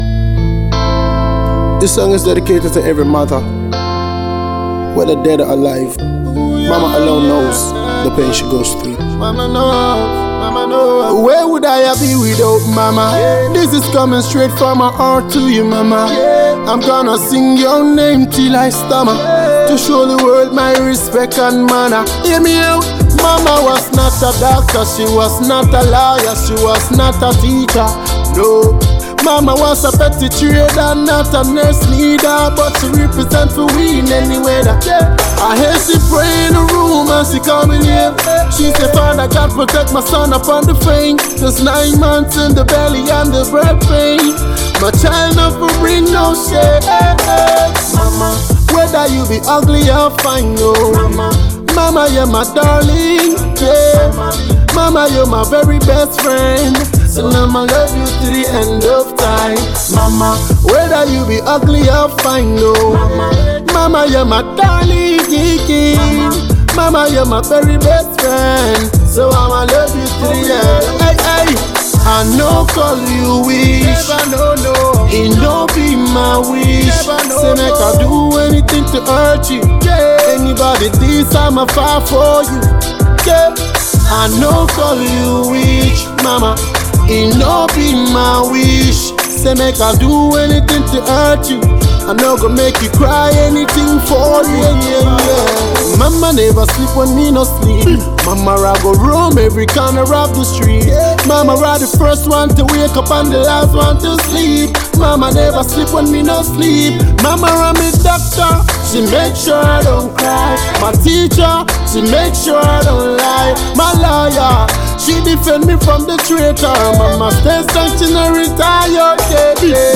Reggae
Soft reggae Tune